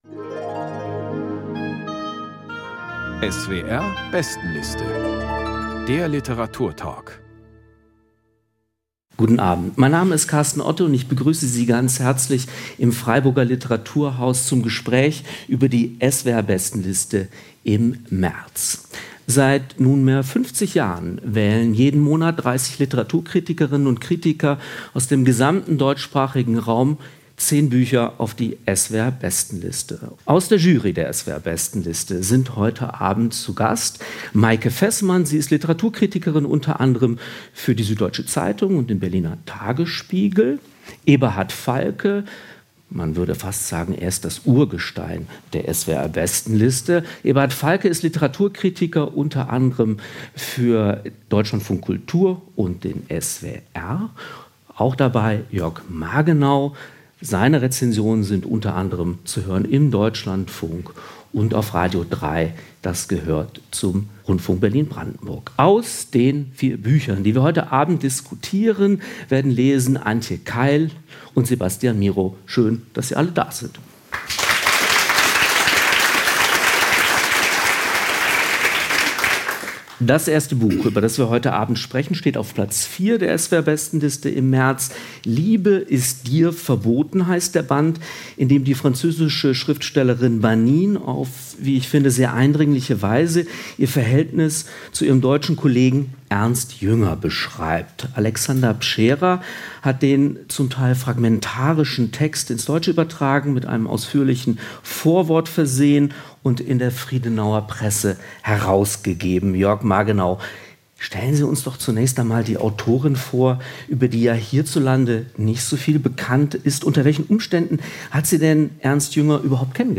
Ein vielleicht gar nicht mal so überraschendes Ergebnis lieferte die Bestenliste-Runde im Freiburger Literaturhaus. Werke, die auf die Vergangenheit zurückblicken, führten nicht selten zu hitzigen Diskussionen über die Gegenwart.